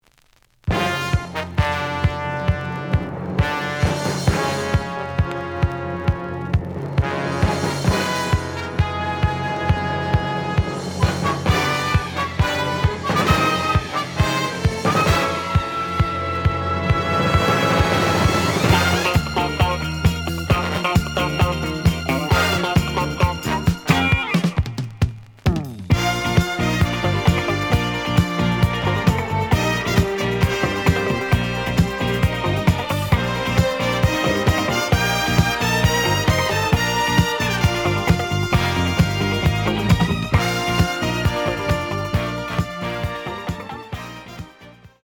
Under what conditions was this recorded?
(Mono) The audio sample is recorded from the actual item. ●Format: 7 inch